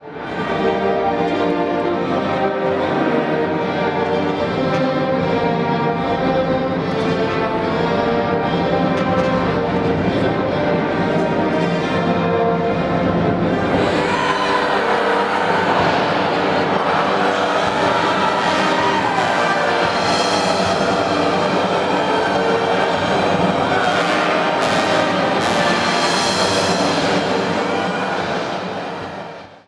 Auszug aus dem Gewitter